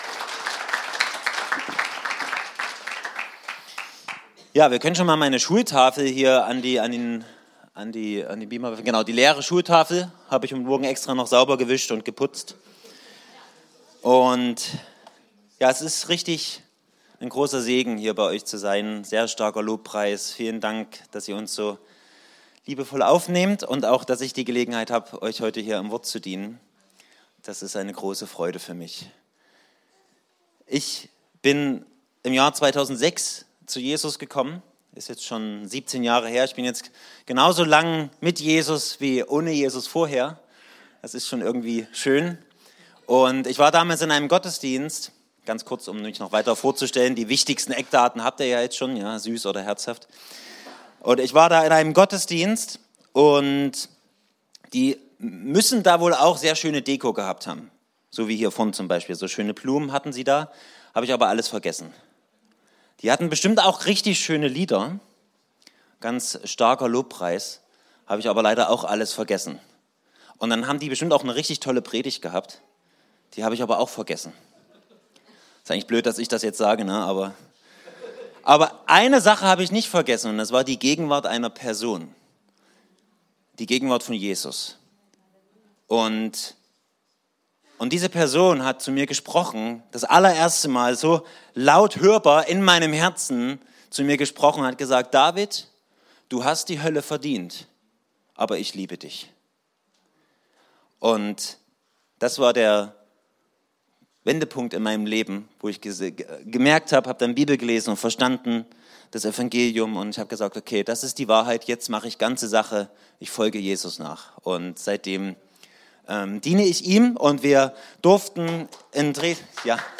Die Predigt wurde gehalten am 4.Juni 2023 im Gottesdienst der Josua Gemeinde in Zagelsdorf:
Predigt-Podcast